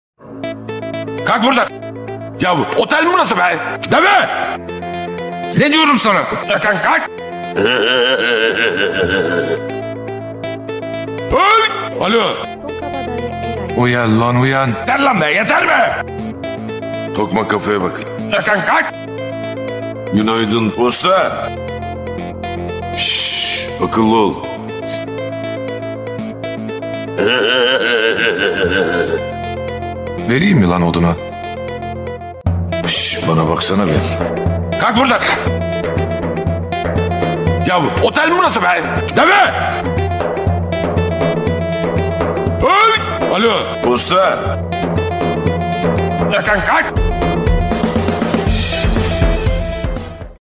Kategori: Zil Sesleri